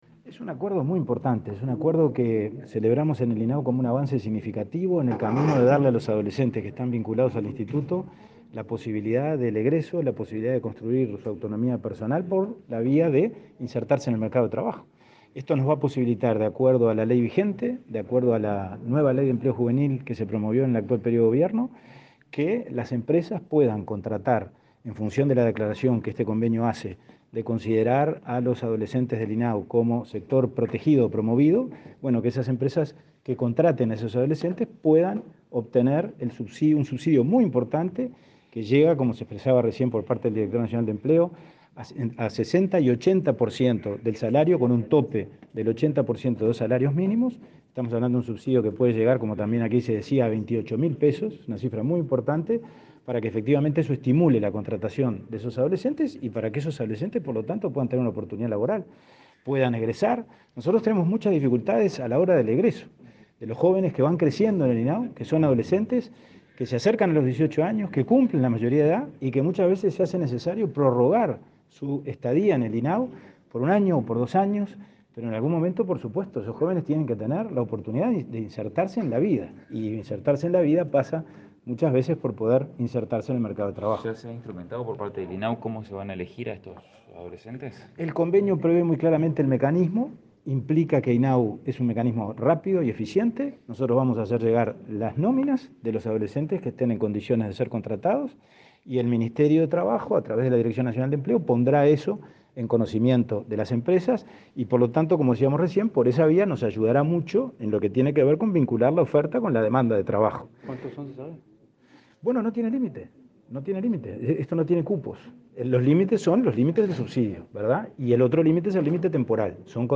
Declaraciones a la prensa del presidente del INAU, Pablo Abdala
Autoridades del Ministerio de Trabajo y el Instituto del Niño y el Adolescente del Uruguay (INAU) firmaron este jueves 11 un acuerdo, en el marco de la Ley de Promoción de Empleo, n.º 19.973, para fomentar la contratación de jóvenes del instituto. El presidente del INAU, Pablo Abdala, dialogó con la prensa acerca del alcance del convenio.